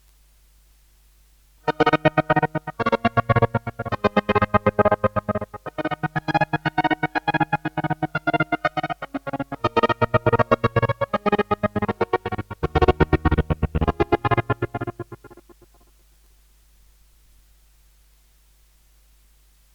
This is the default 'boomchik' pattern that it boots up with. Chords are from an old Roland general midi box.
I like the sound you've got there, very 90s euro techno :)
ChoppedSynth.mp3